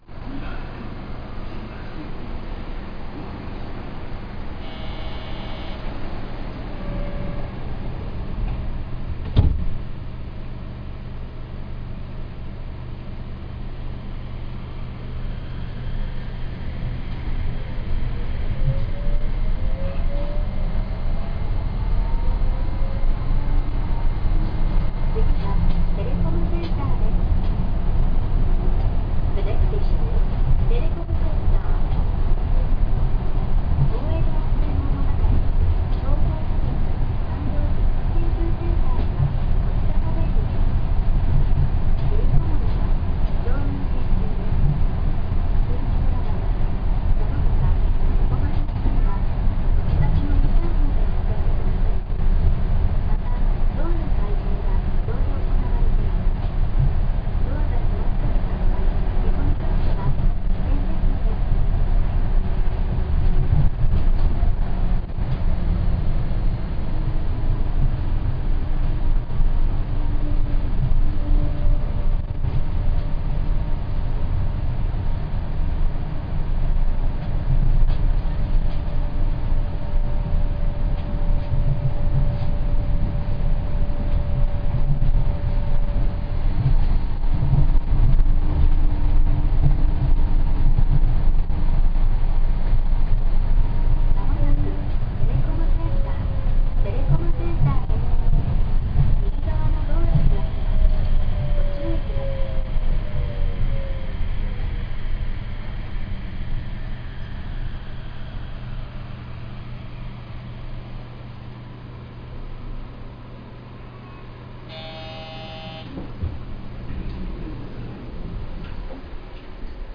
・7200系走行音
【ゆりかもめ】青海→テレコムセンター
モーター音自体はそんなに目立ちません。